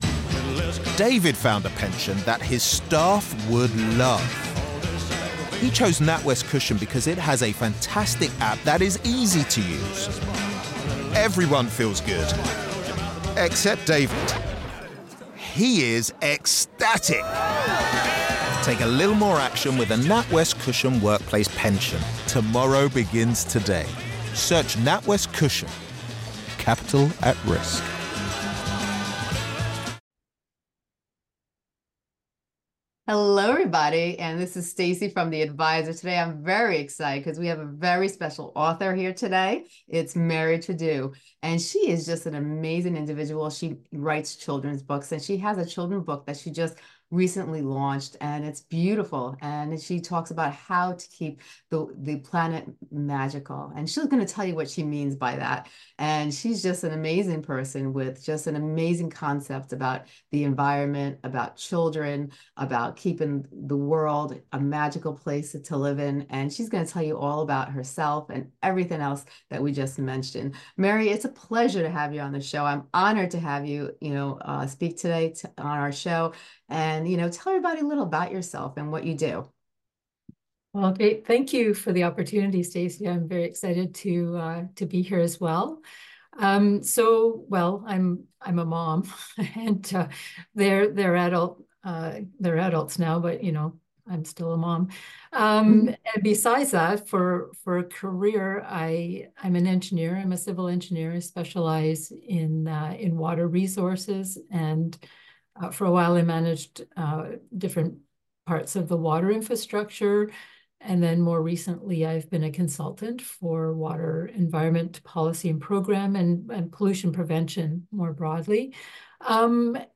Unlocking Environmental Awe: A Conversation